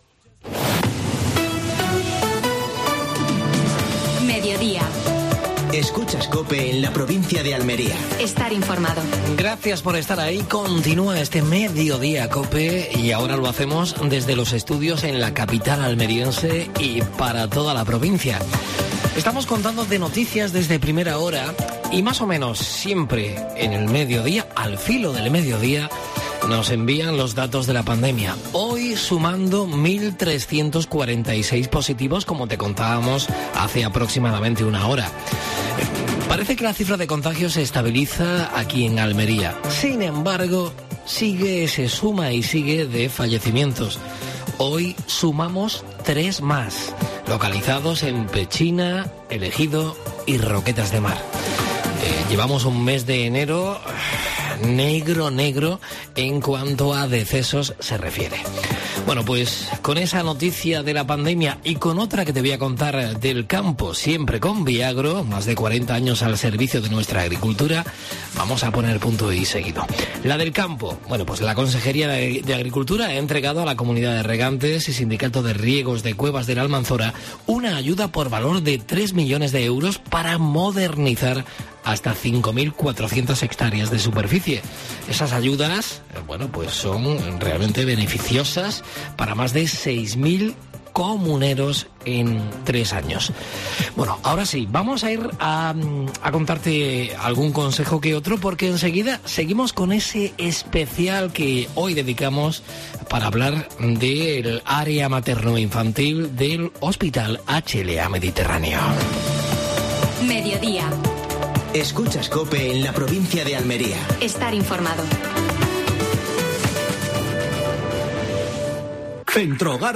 Actualidad en Almería. Entrevista